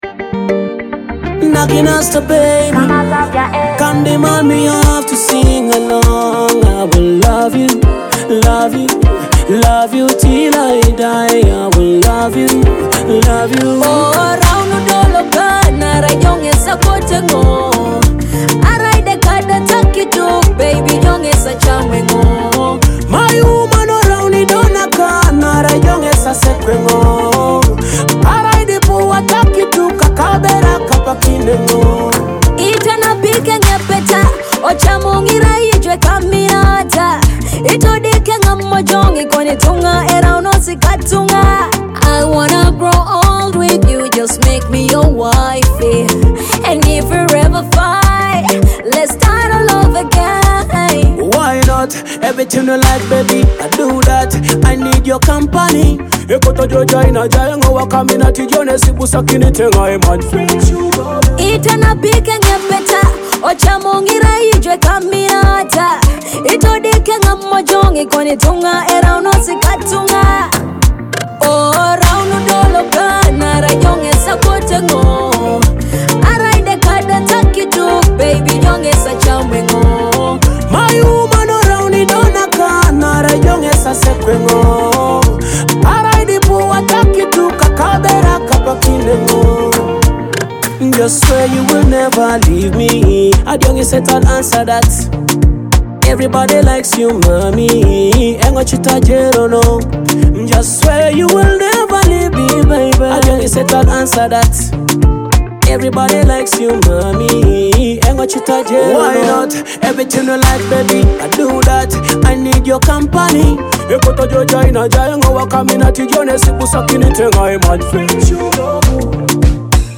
soulful Teso love song
mesmerizing love dancehall track